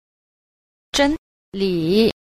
10. 真理 – zhēnlǐ – chân lý